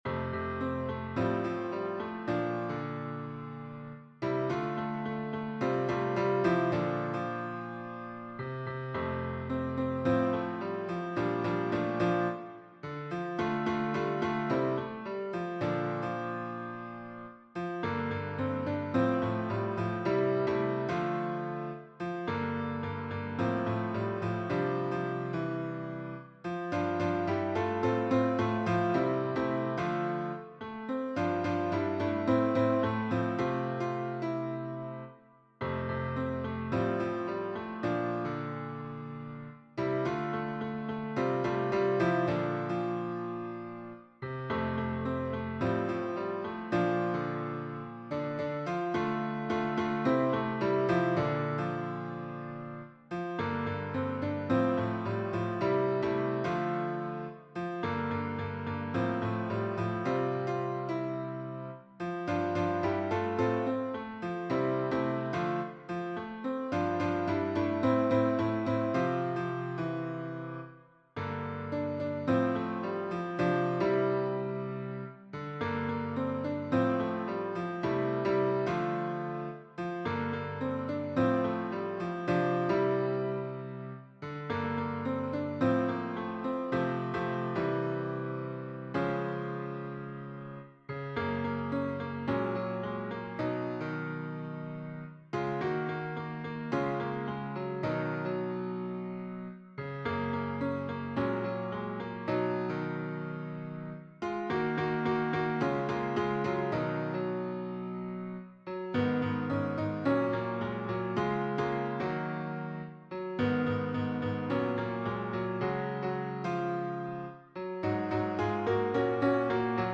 TTBB ensemble